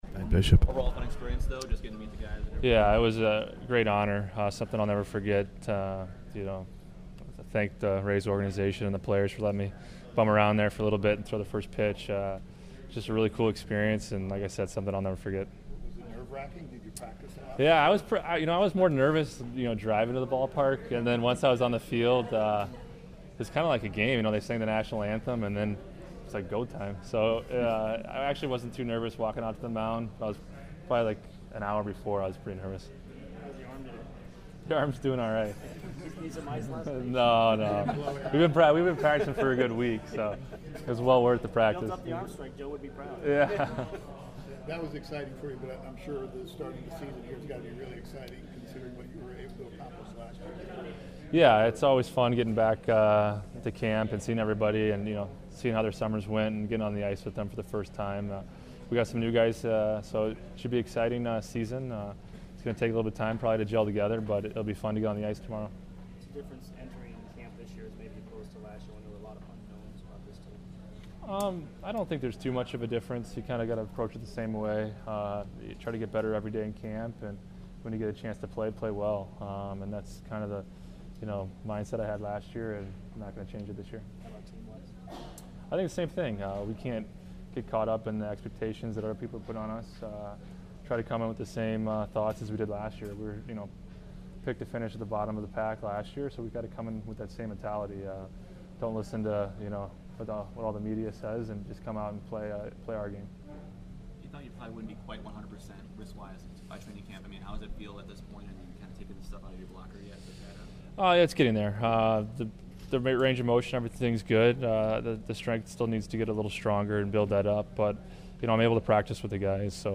Ben Bishop speaks to the media during Thursday's media day.